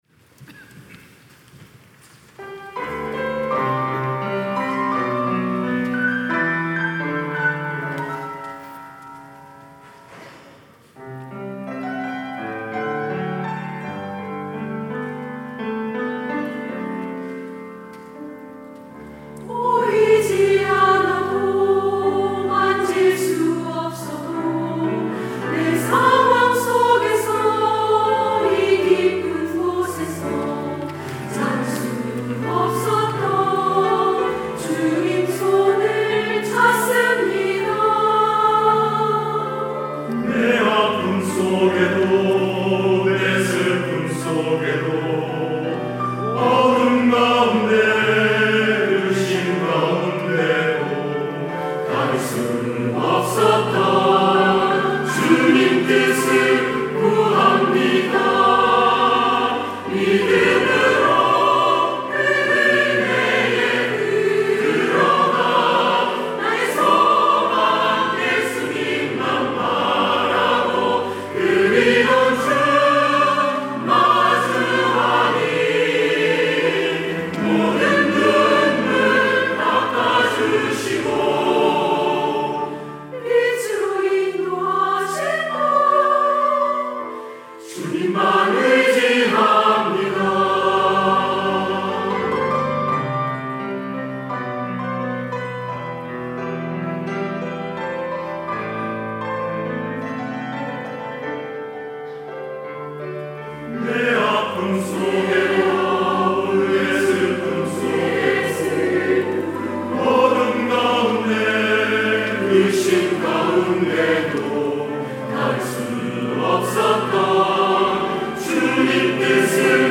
할렐루야(주일2부) - 믿음으로
찬양대